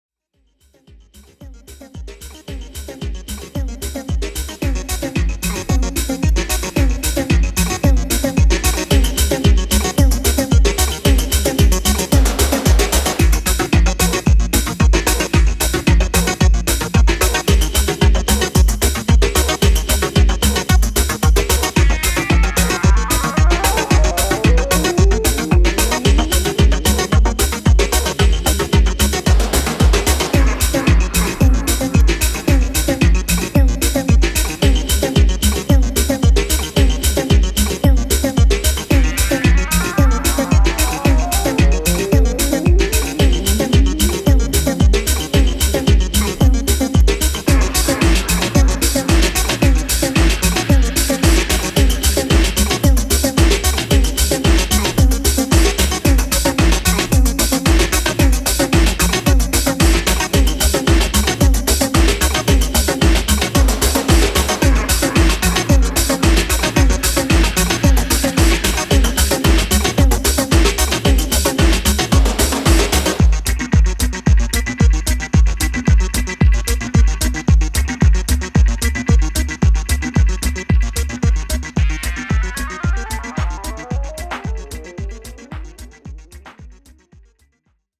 サイケデリックなテクスチャーでコズミック・サウンドをオマージュした